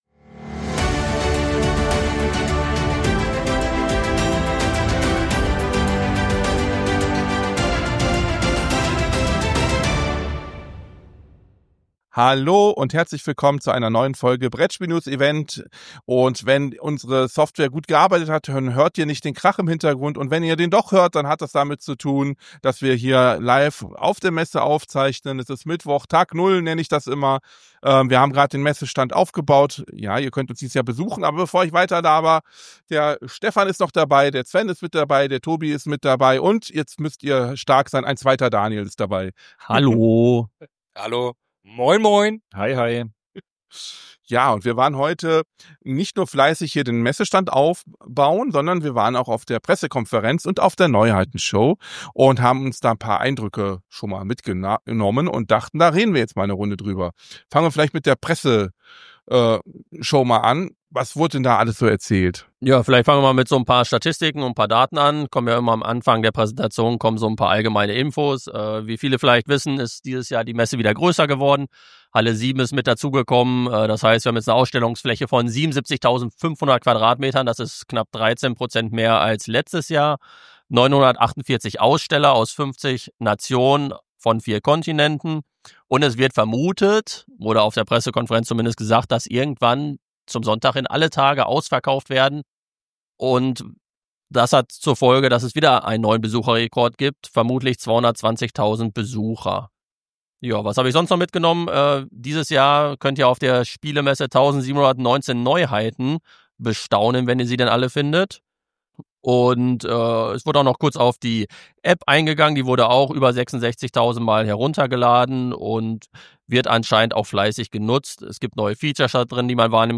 In dieser Episode berichten wir live von der Spielmesse und nehmen Sie mit auf eine spannende Tour durch die neusten Entwicklungen und Trends in der Brettspielbranche.